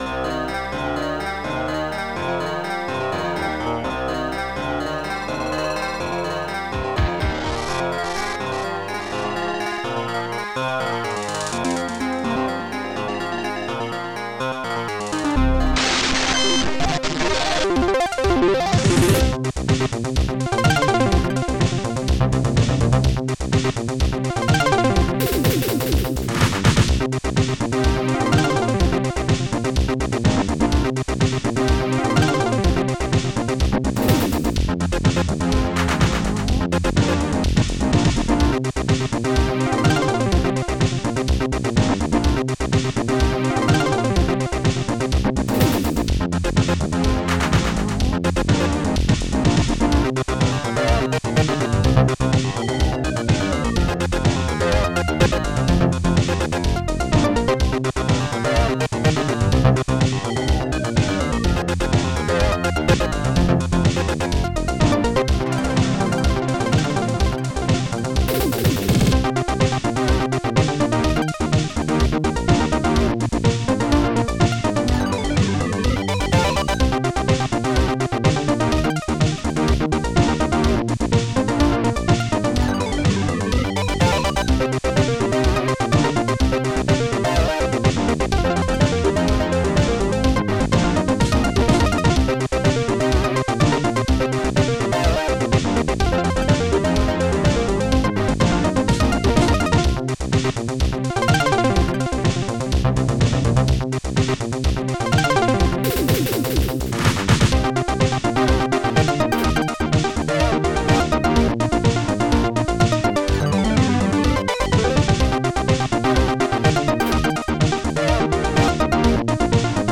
Protracker and family
ST-04:ANIMATE-KICK
ST-01:SNARE5
ST-04:ANIMATE-CLAP